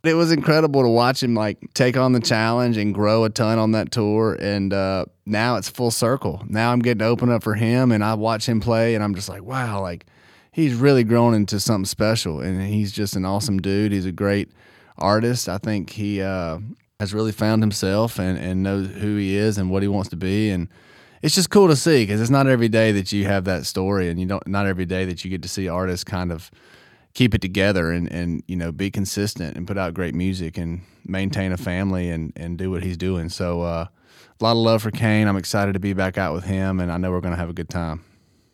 Tyler Hubbard says he's experiencing a full circle moment opening up for Kane Brown on his In The Air Tour.